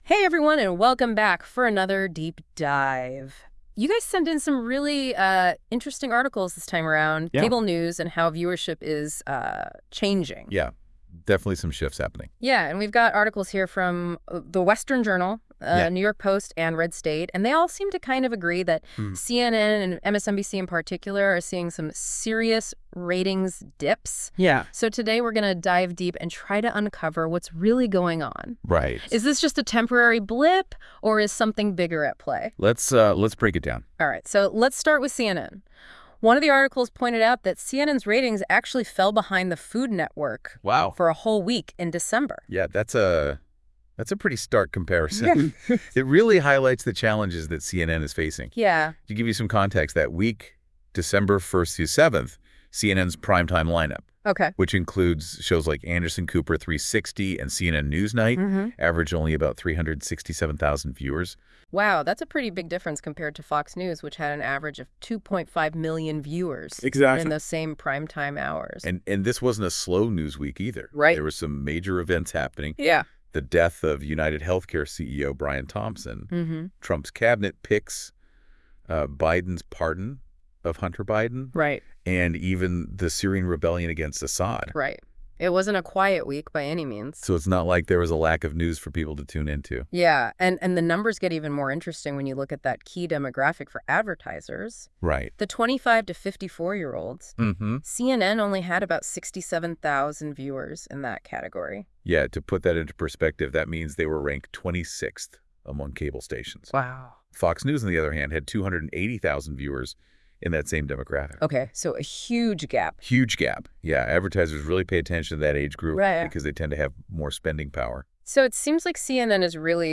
Listen to a convo on 3 articles on it all…. much more below